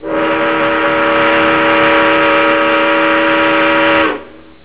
Cŕŕn Dlhé piskanie parnej lokomotivy 0:04